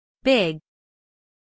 big-stop-us-female.mp3